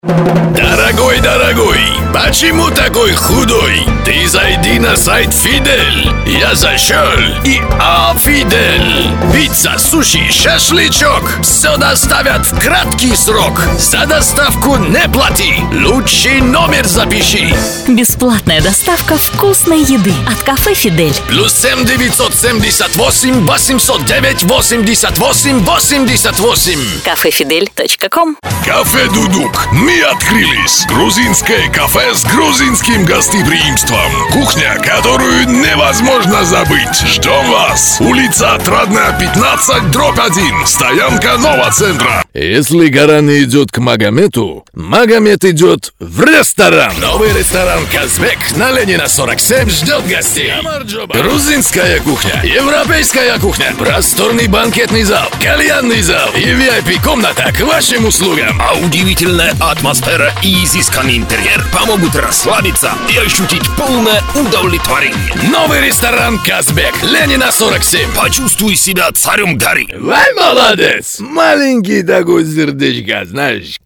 УНИВЕРСАЛЬНЫЙ диктор. Артист *игровых* ролей -от комедии до драмы, и наоборот.
RODE 1000,Neumann 87